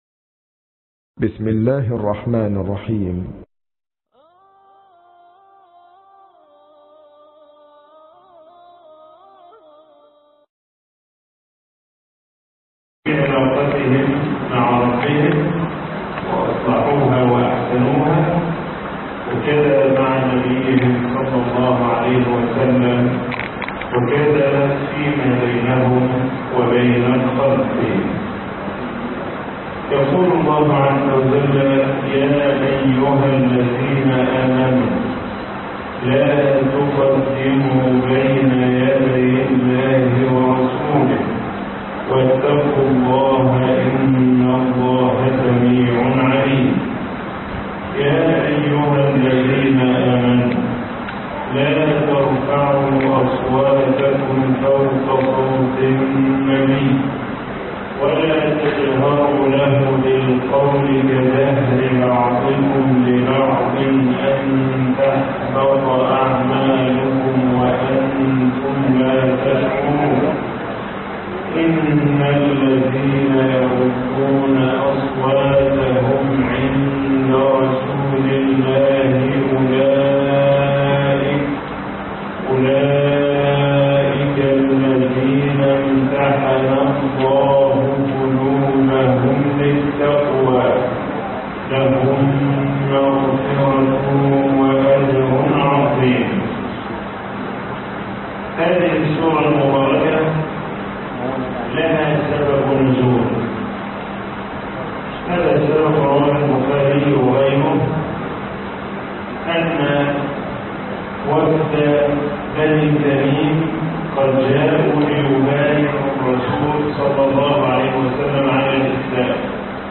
تفسير من الآية 1 الى 3 (سورة الحجرات)